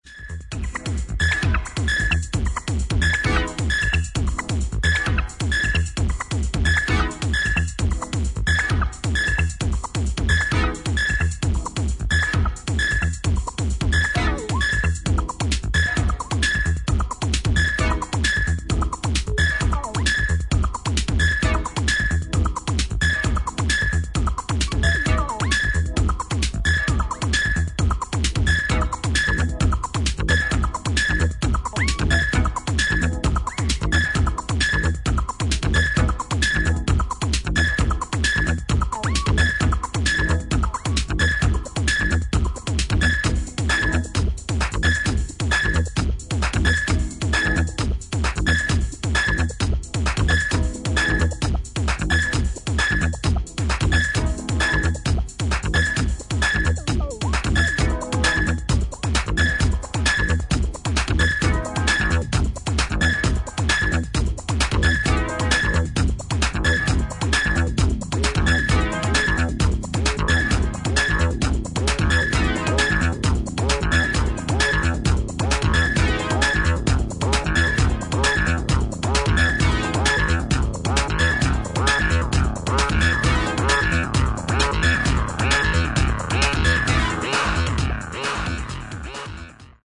サイファイなシンセサウンドと強靭なビートがマッチした